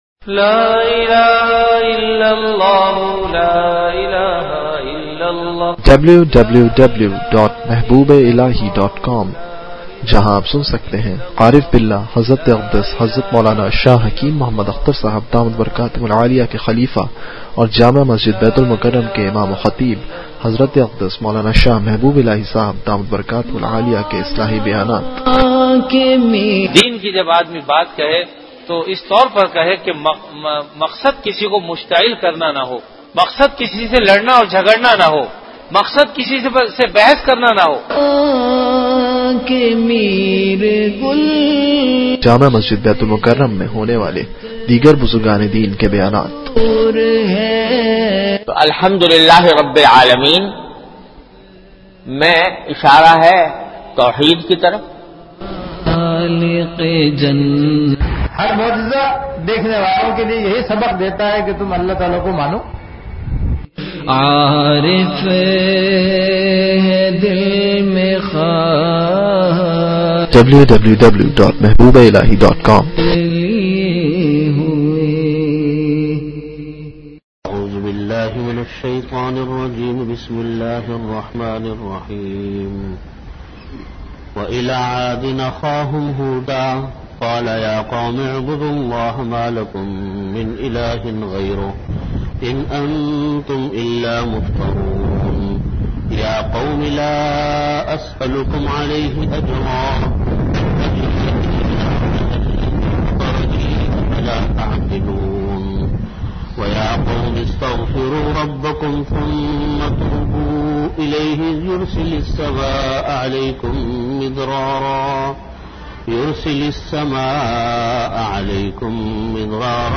Bayanat · Jamia Masjid Bait-ul-Mukkaram, Karachi
Tafseer Lectures Have a question or thought about this bayan?